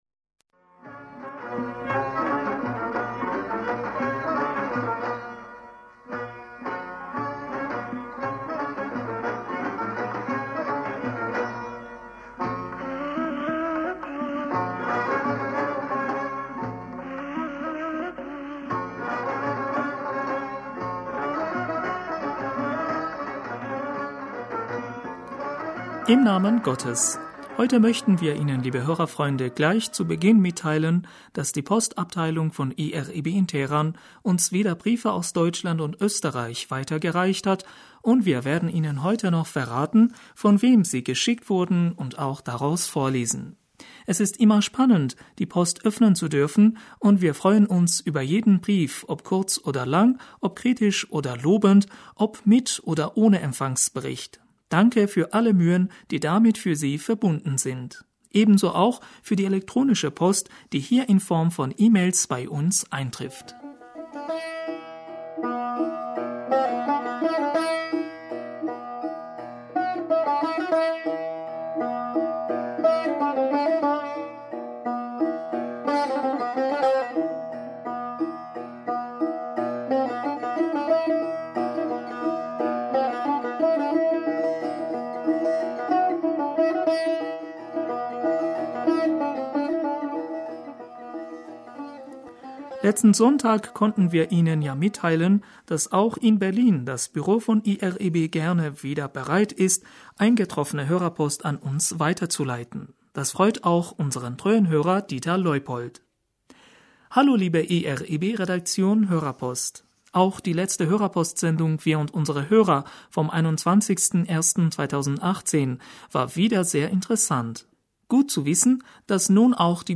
Hörerpostsendung am 28. Januar 2018 - Bismillaher rahmaner rahim - Heute möchten wir Ihnen liebe Hörerfreunde gleich zu Beginn mitteilen, dass die...